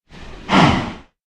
snapshot / assets / minecraft / sounds / mob / cow / say4.ogg